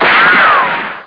GUNSHOT3.mp3